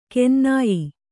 ♪ kennāyi